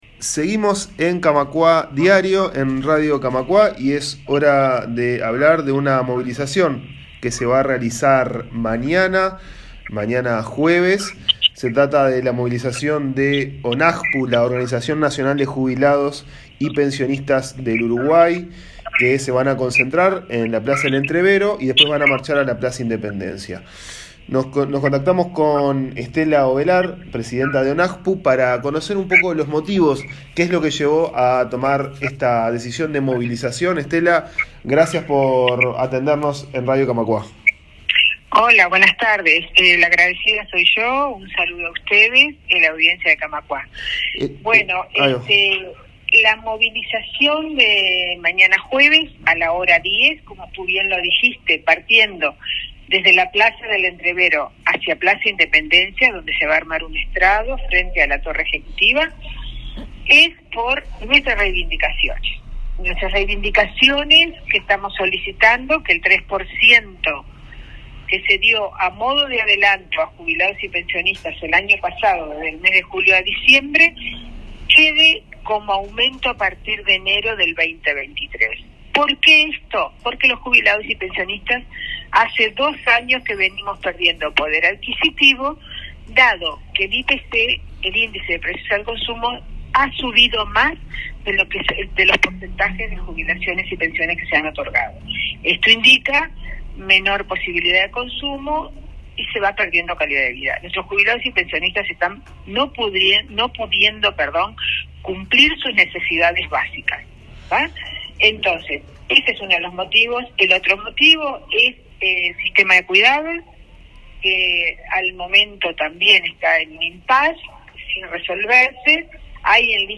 Recibimos en Camacuá Diario a los integrantes del Consejo Directivo Autónomo (CDA) de Jubilados y Pensionistas de AEBU.